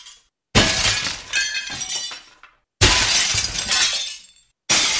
glass_breaking54.wav